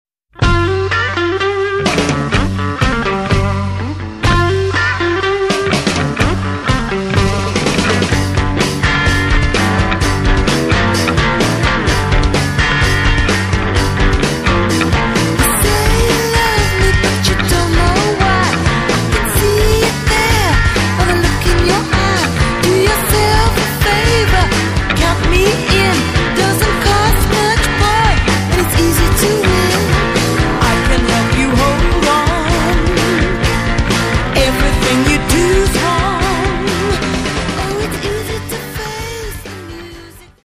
GIRLS POP/FREAK BEAT